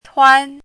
chinese-voice - 汉字语音库
tuan1.mp3